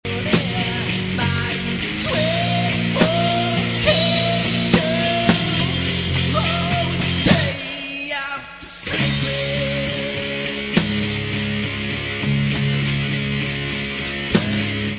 pop indie